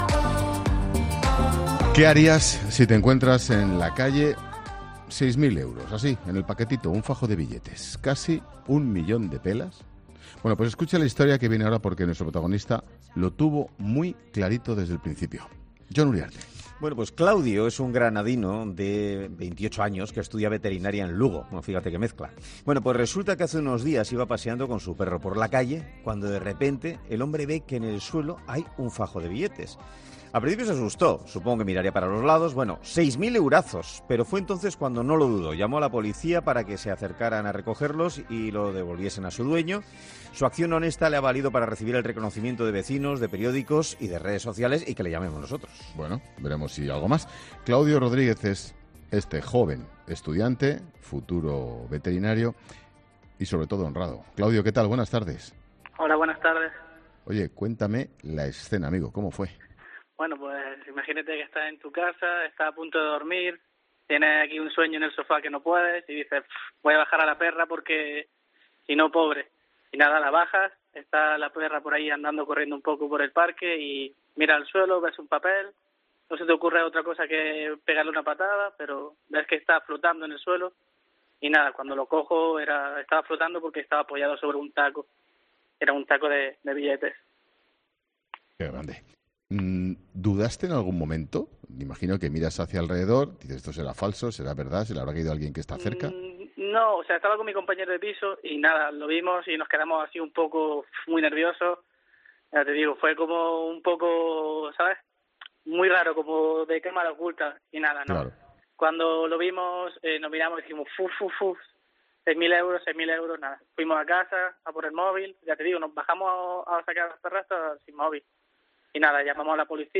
En 'La Tarde' hemos hablado con él para que nos cuente cómo vivió la situación: "Estaba con mi compañero de piso y nos pusimos nerviosos, fue muy raro, de cámara oculta.